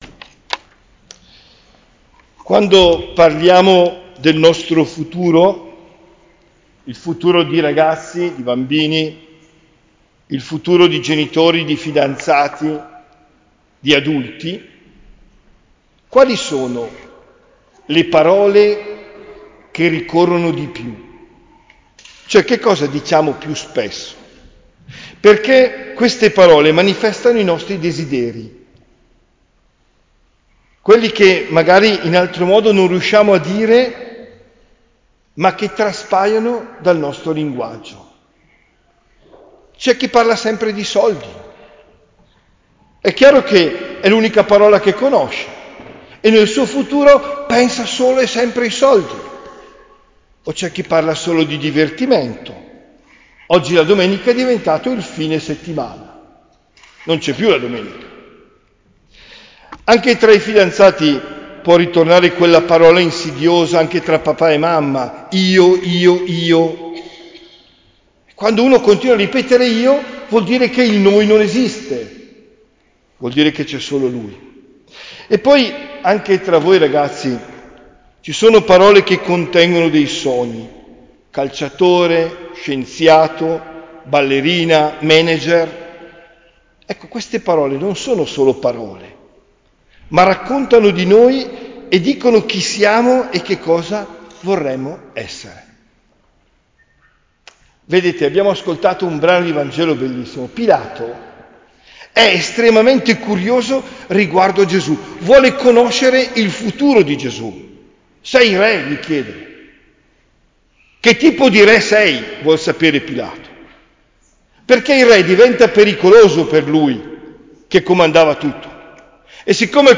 OMELIA DEL 24 NOVEMBRE 2024